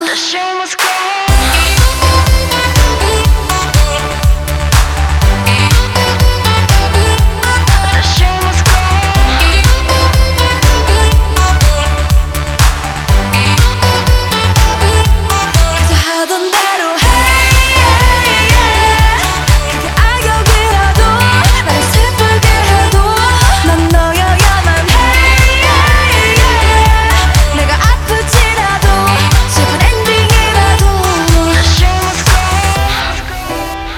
• Качество: 320, Stereo
танцевальные
K-Pop